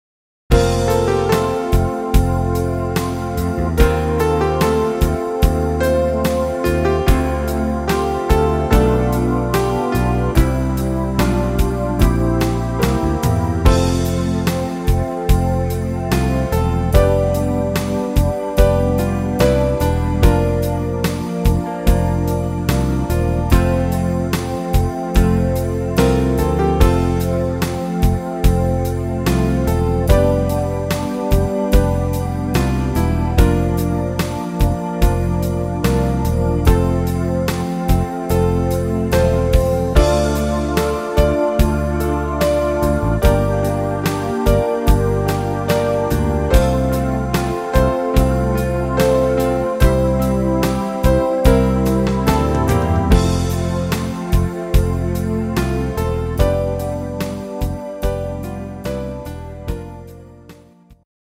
Rhythmus  Slow
Art  Englisch, Oldies